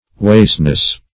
wasteness - definition of wasteness - synonyms, pronunciation, spelling from Free Dictionary
Wasteness \Waste"ness\, n.